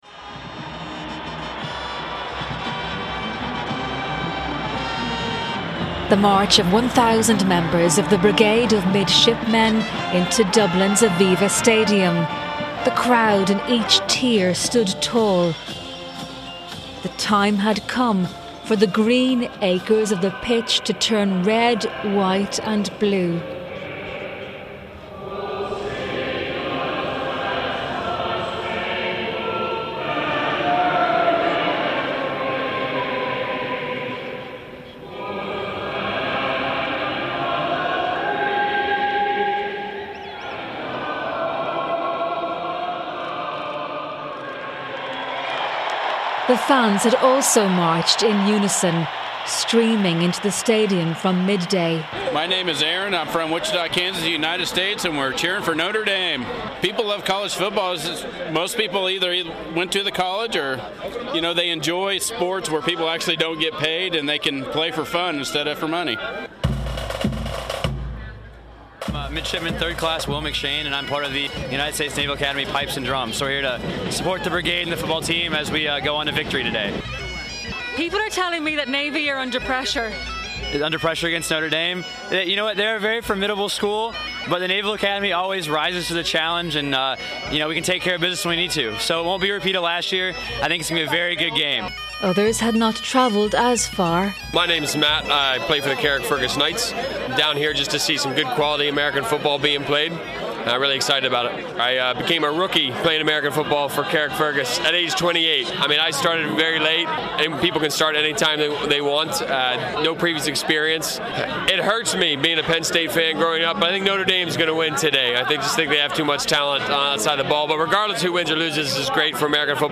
Some 50,000 American Football fans gathered at the Aviva Stadium in Dublin on September 1st to watch Notre Dame take on Navy, the first United States college game in Ireland in 16 years. I was pitchside .........(Broadcast on Good Morning Ulster Sept 3)